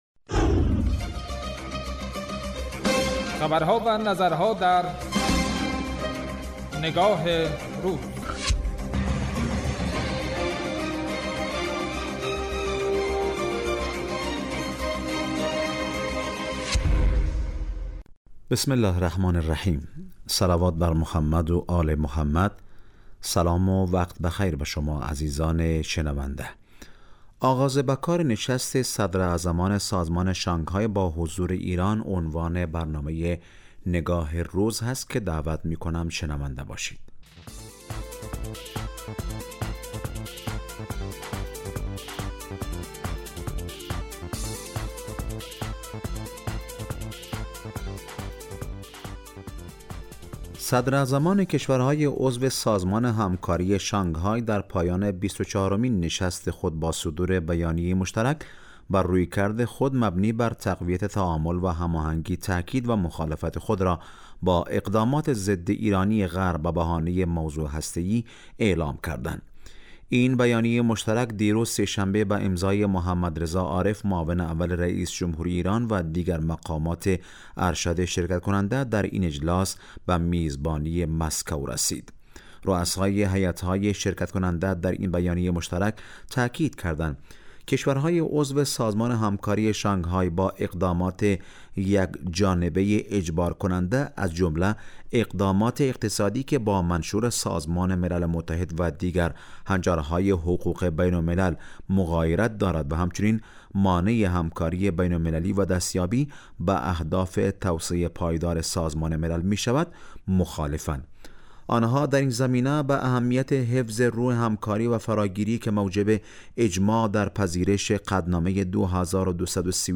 برنامه تحلیلی نگاه روز